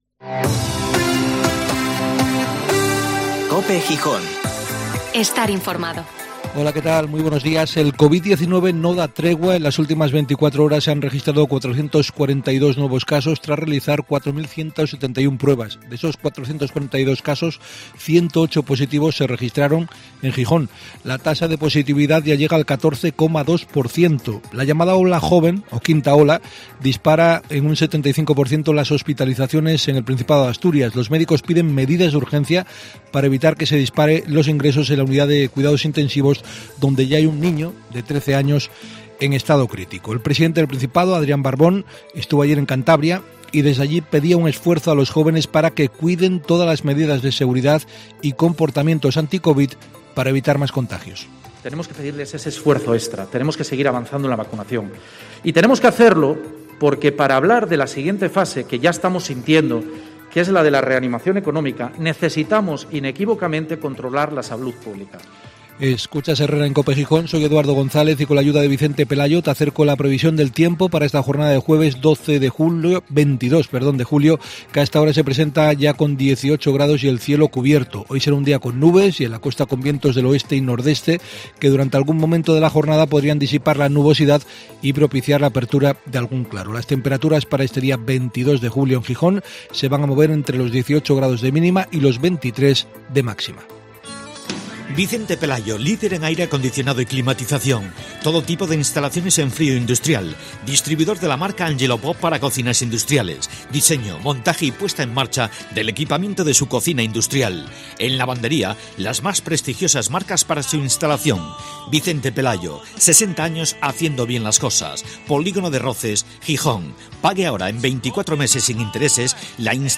Informativo matinal HERRERA en COPE Gijón (Jueves 22/Julio/2021)